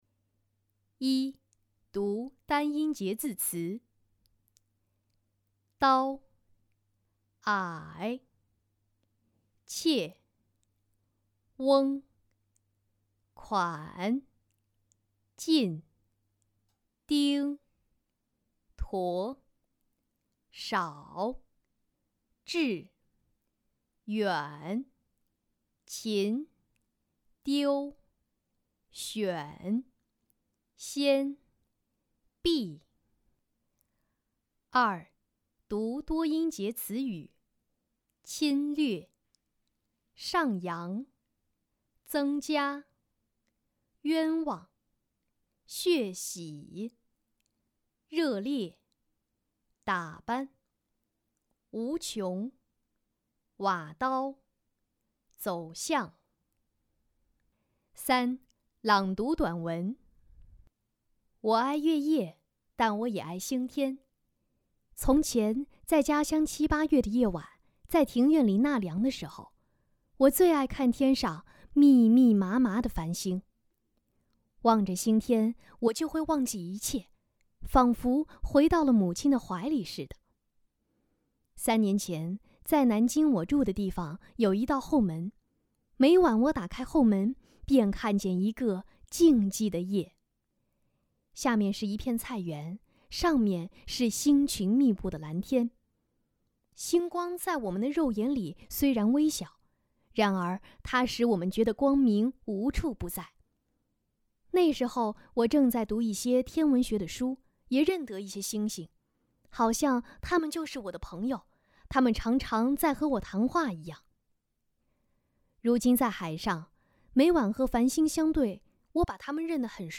练习音频
感谢积极参与此次“领读周周学”课件录制的“生活好课堂”朗读专业志愿者！